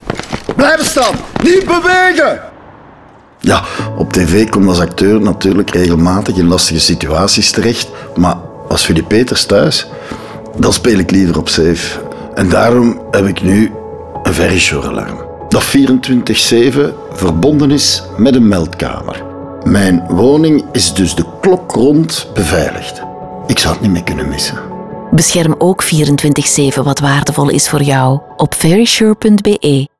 RADIO - SAFE - 30"
250224_Verisure_NL30s_Radio_Safe.mp3
Sound Studio: Raygun